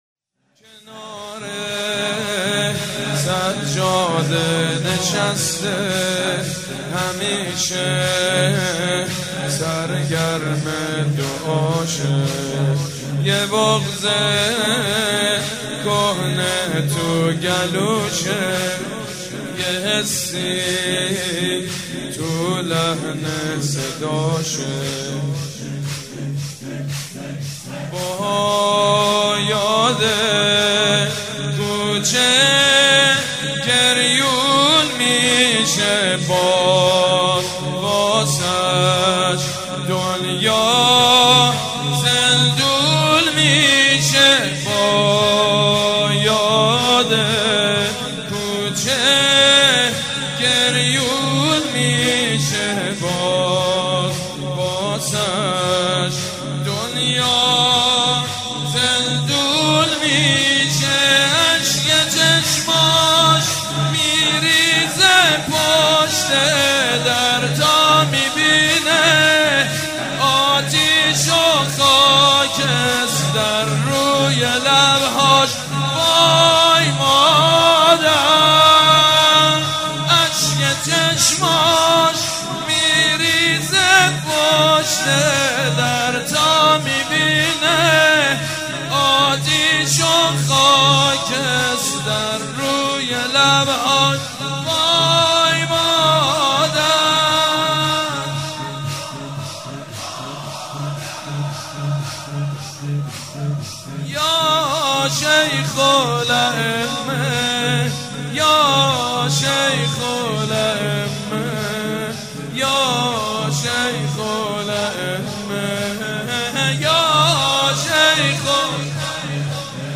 به مناسبت شهادت ششمین امام شیعیان امام جعفرصادق(ع) مداحی به این مناسبت با نوای سیدمجید بنی فاطمه ارائه می‌شود.